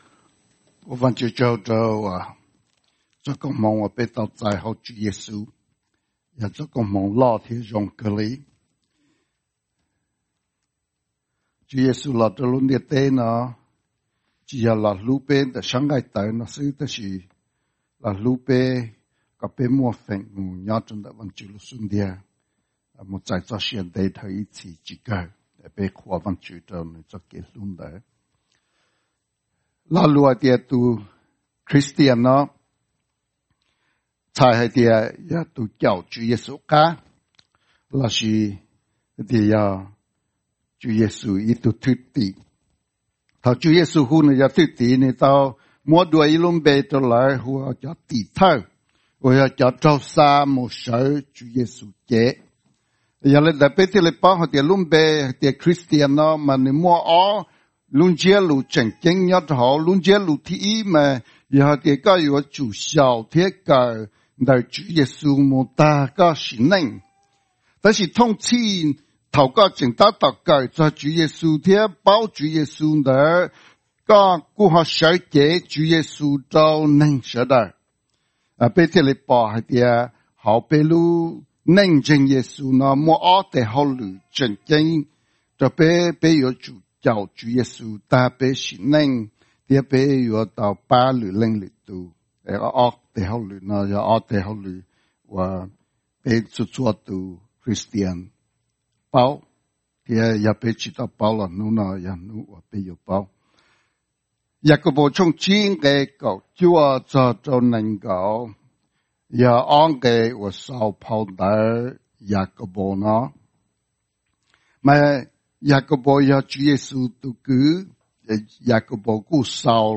Sermons Archive - Eternal Life Church
2019-08-18 – English Service